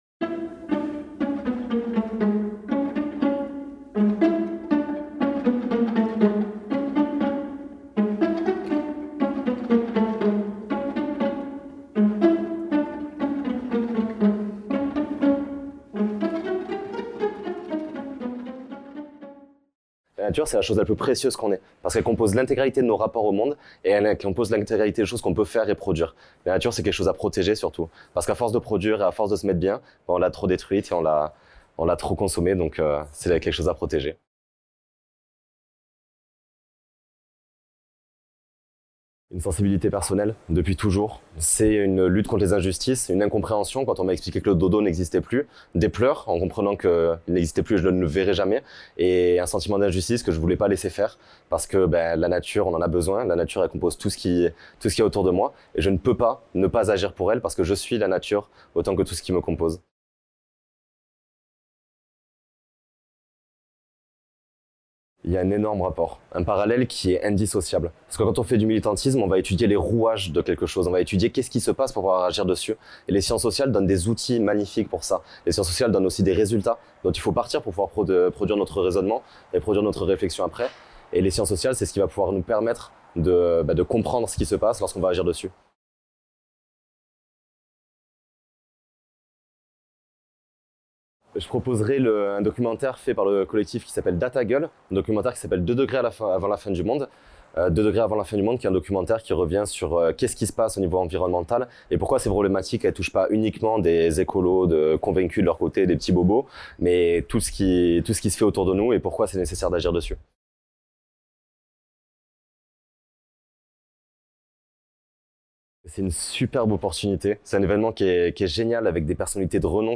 Favoris Ajouter à une playlist Entretien